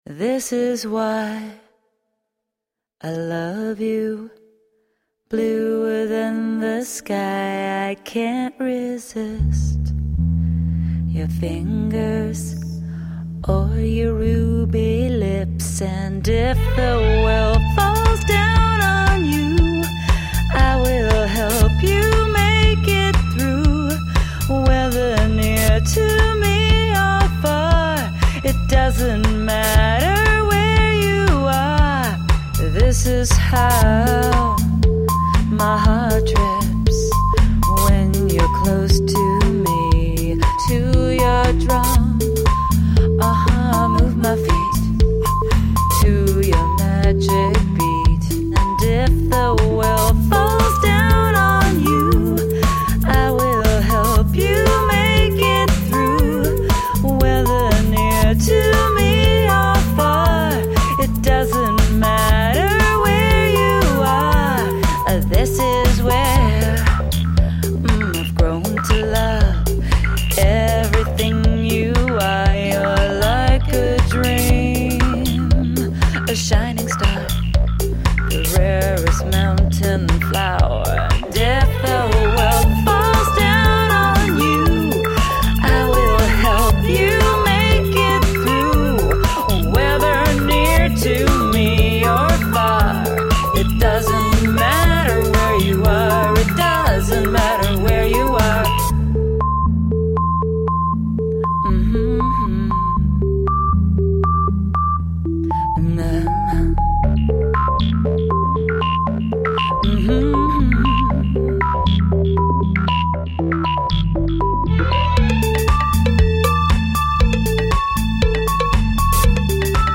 alternative/rock band
The infectious grooves and luscious melodies
five octave range voice
Some call it post modern post punk electro-acoustic hybrid.
Tagged as: Alt Rock, Pop